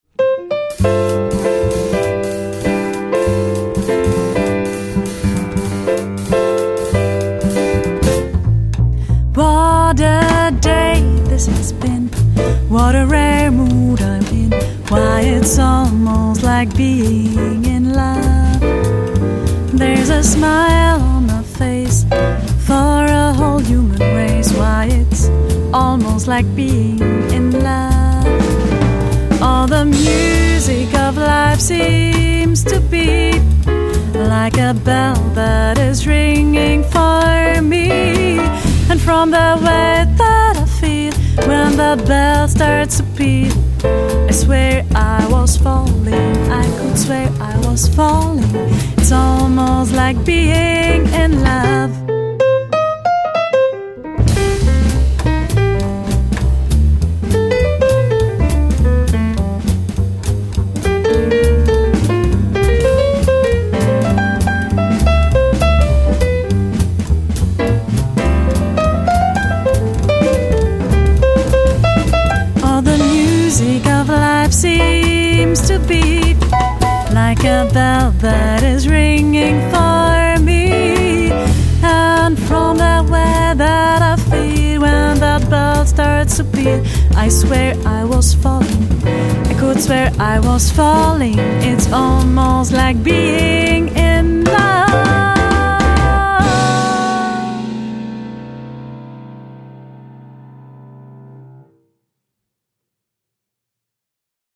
elegantes hauptstadt-quartett